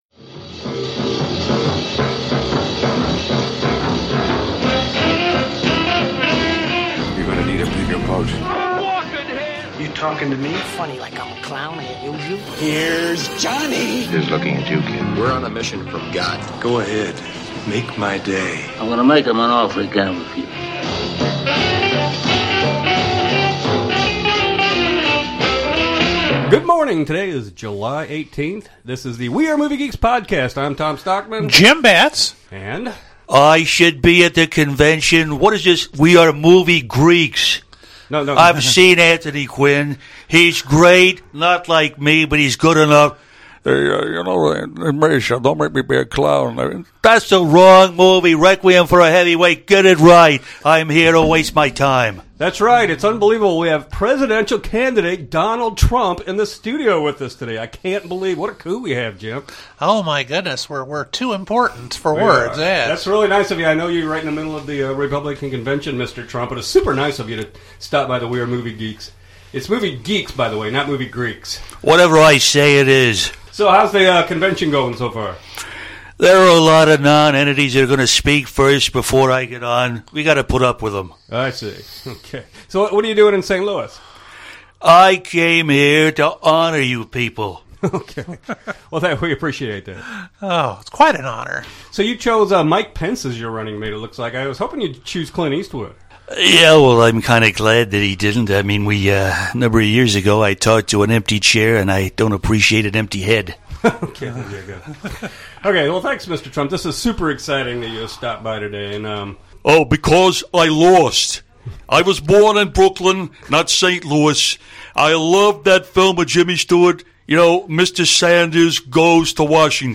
Presidential candidate Donald Trump will stop by the studio!